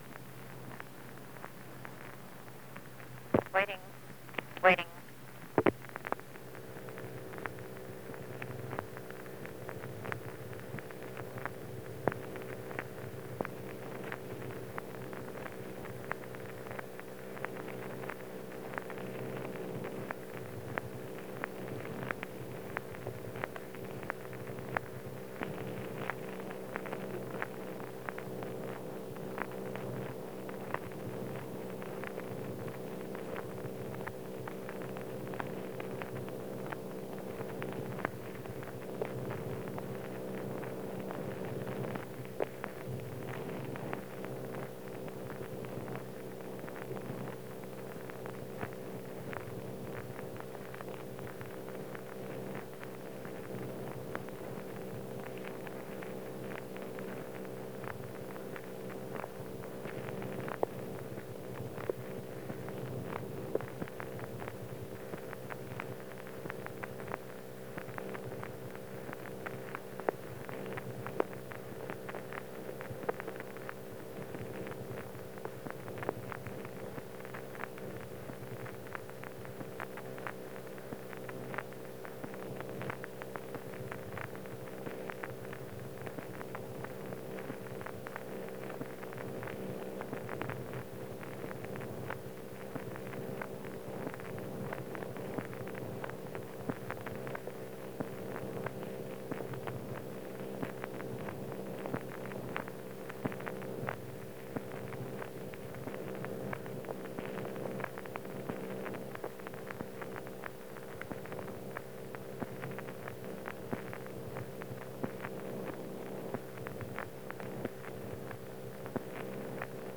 Secret White House Tapes | John F. Kennedy Presidency